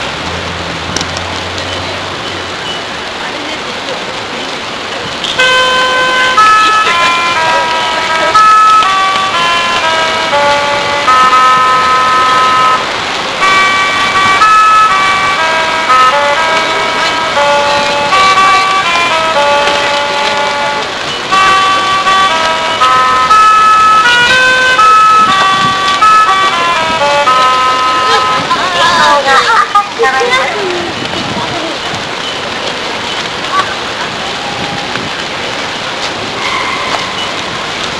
最後に訪れた静岡県でのネタです。
最後の1フレーズから入り、最初に戻るといった感じです。
また、静岡県では珍しく点滅時に「信号が赤に変わります」というアナウンスが流れます。